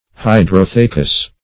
Search Result for " hydrothecas" : The Collaborative International Dictionary of English v.0.48: Hydrotheca \Hy`dro*the"ca\, n.; pl.